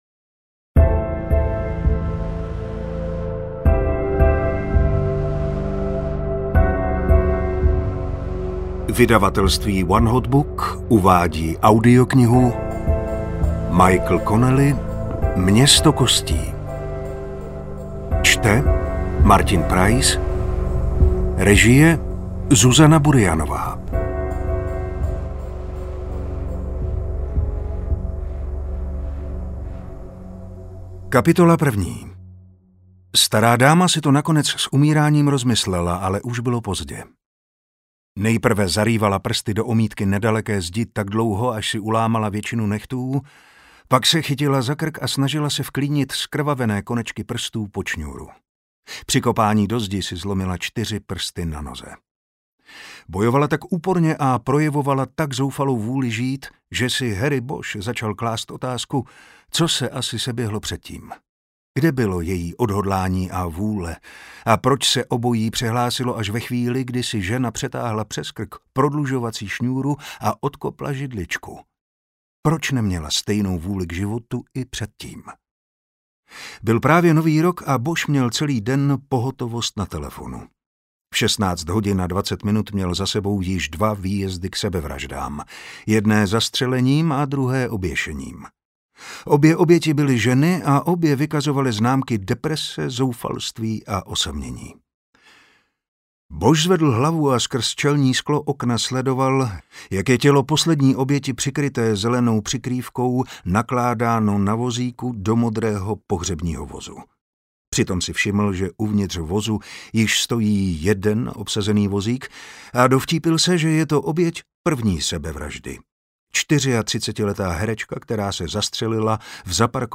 Město kostí audiokniha
Ukázka z knihy
• InterpretMartin Preiss